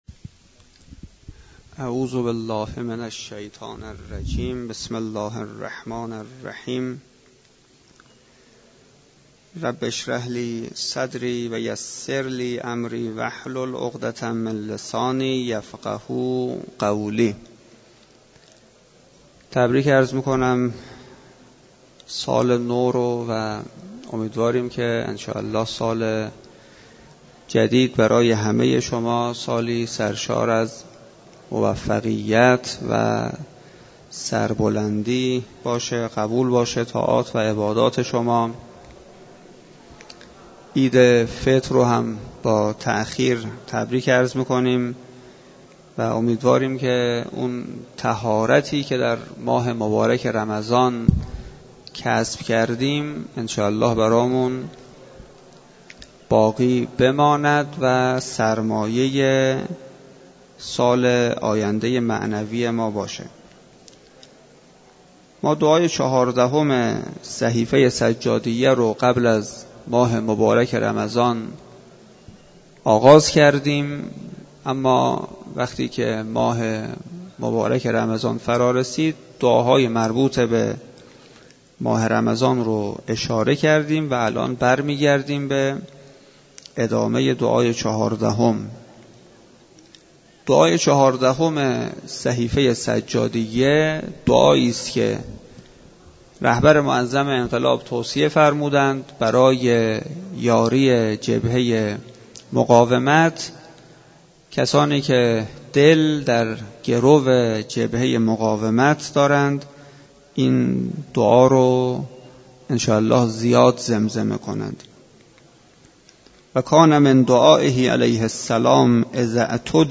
سخنرانی
در مسجد دانشگاه کاشان برگزار گردید.